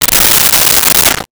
Shower Curtain Open 02
Shower Curtain Open 02.wav